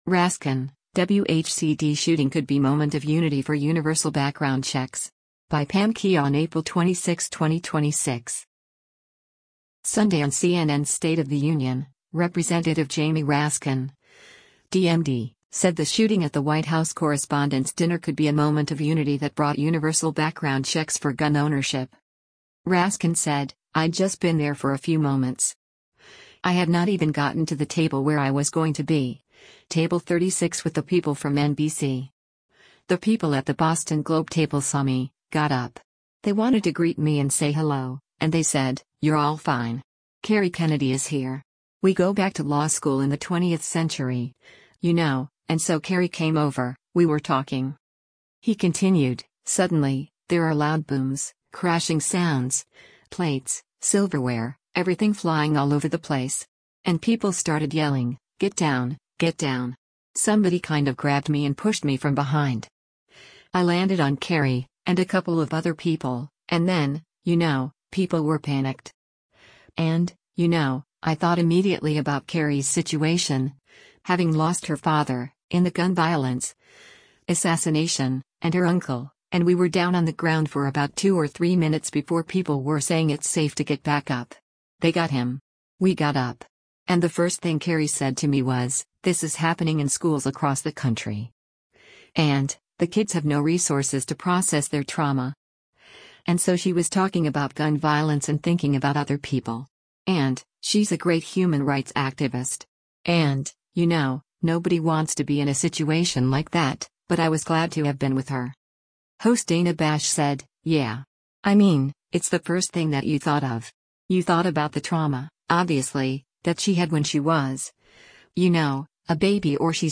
Sunday on CNN’s “State of the Union,” Rep. Jamie Raskin (D-MD) said the shooting at the White House correspondents’ dinner could be a moment of unity that brought universal background checks for gun ownership.